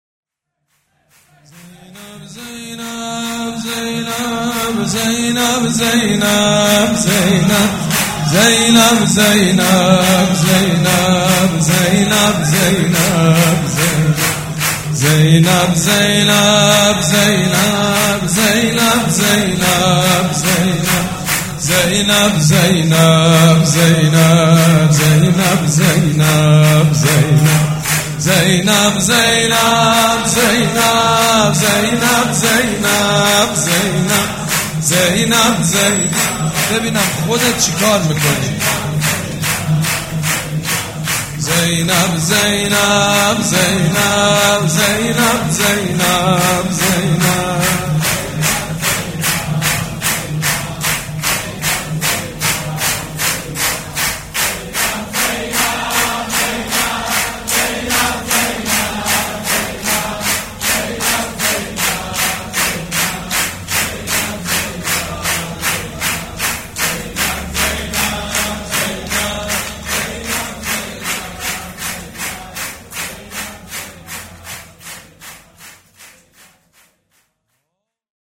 حاج سید مجید بنی فاطمه جمعه 21 دی 1397 هیئت ریحانه الحسین سلام الله علیها
سبک اثــر شور مداح حاج سید مجید بنی فاطمه